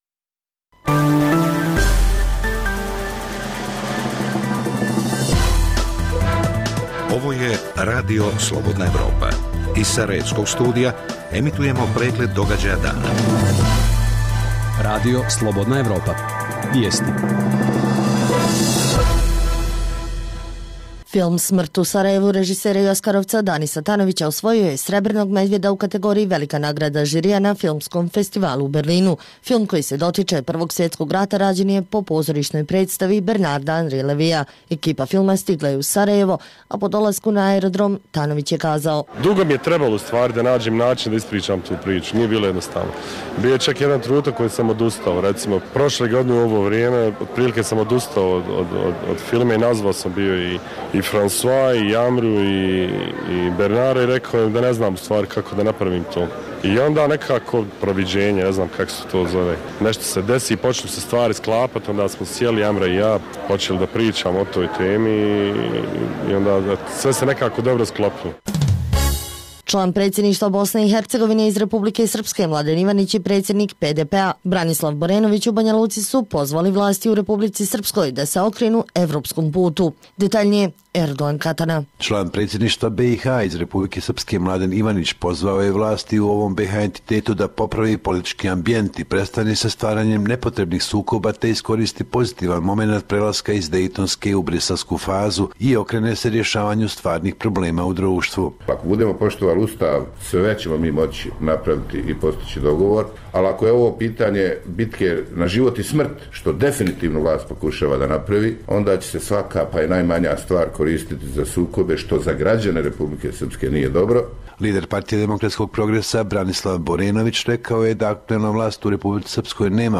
sadrži vijesti, analize, reportaže i druge sadržaje o procesu integracije BiH u Evropsku uniju i NATO.